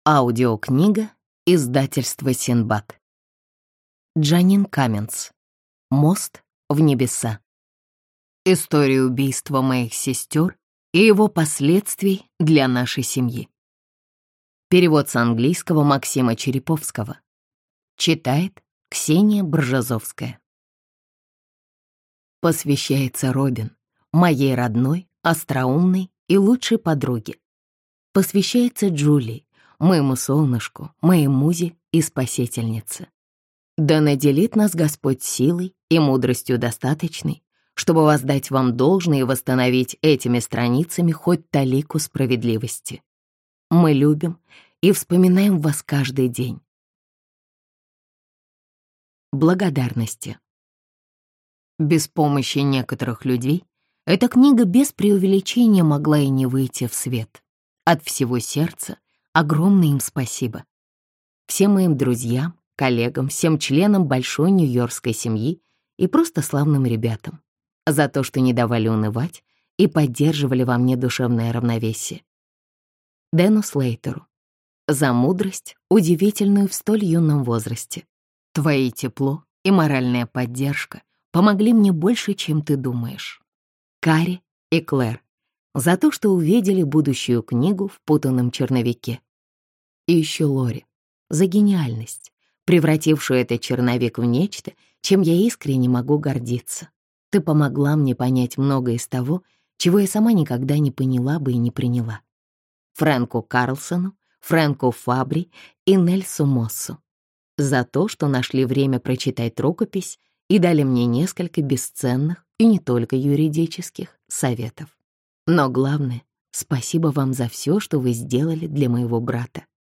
Аудиокнига Мост в небеса. История убийства моих сестер и его последствий для нашей семьи | Библиотека аудиокниг
Прослушать и бесплатно скачать фрагмент аудиокниги